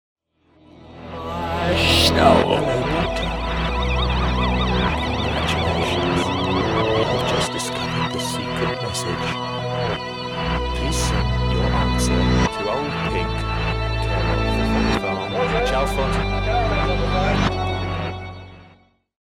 psychedelic rock